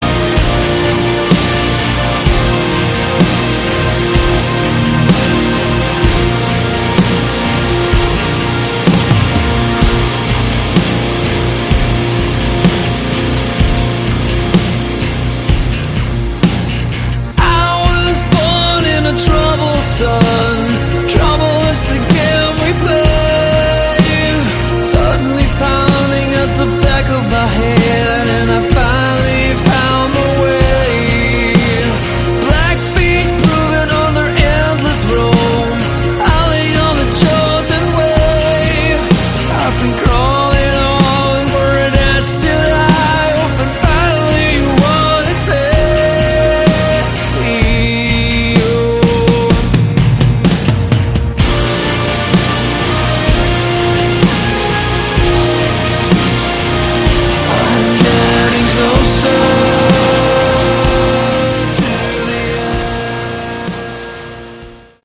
Category: Modern Hard Rock/Electronica
vocals, guitars
bass
keys
drums